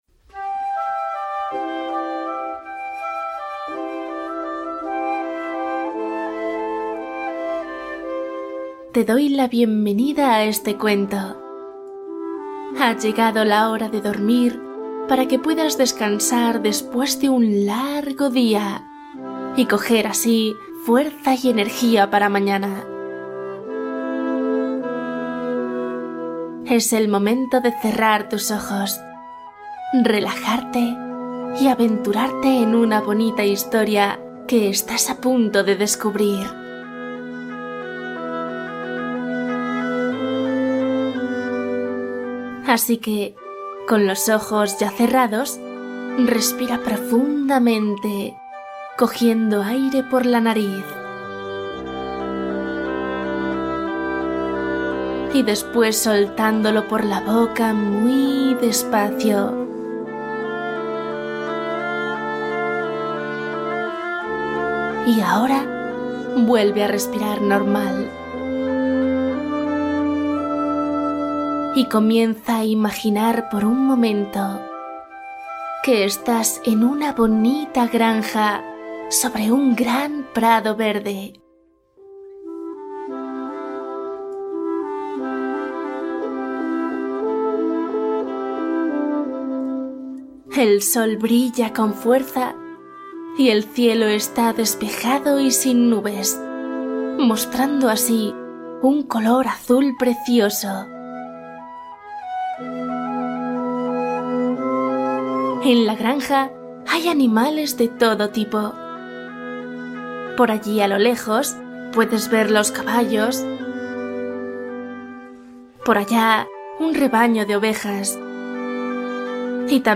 Cuento infantil para dormir: historias con moraleja y dulces sueños